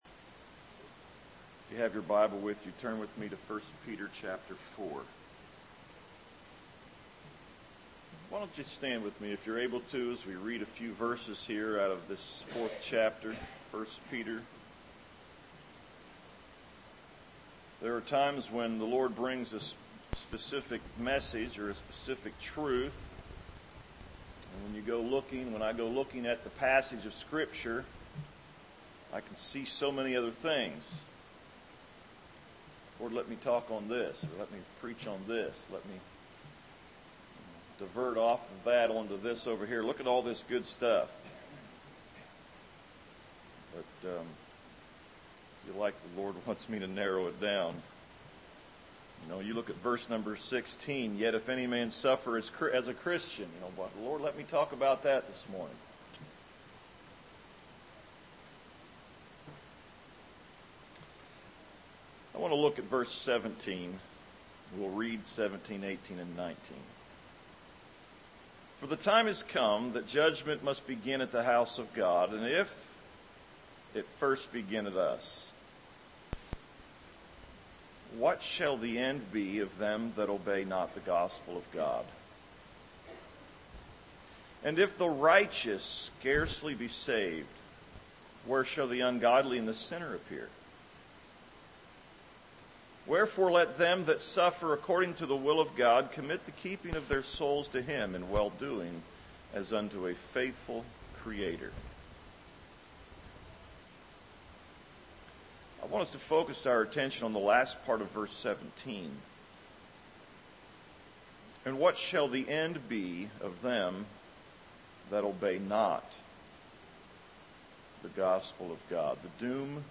Save Audio A sermon